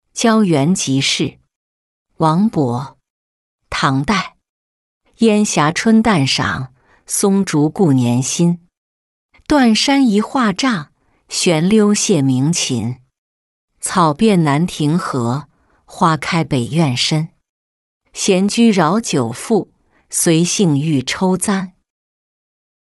郊园即事-音频朗读